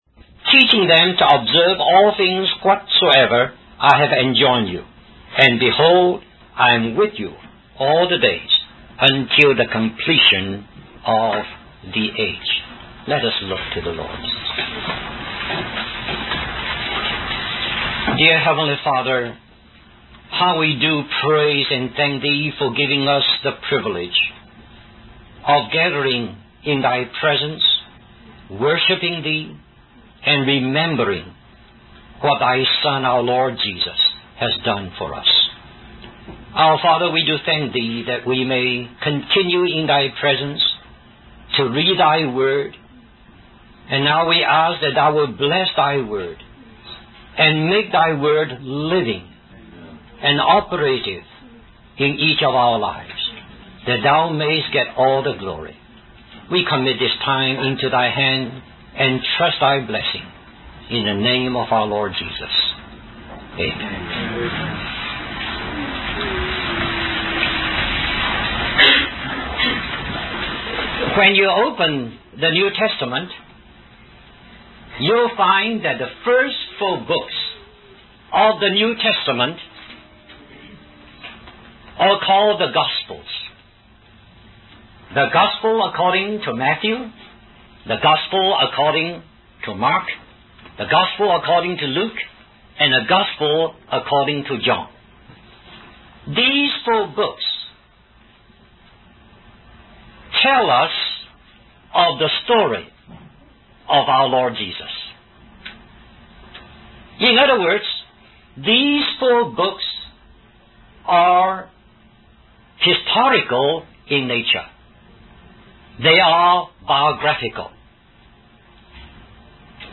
In this sermon, the speaker emphasizes the transformative power of the Word of God.